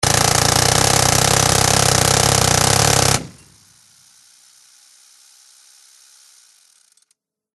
На этой странице собраны реалистичные звуки стрельбы из Минигана.
Звук выстрелов минигана очередью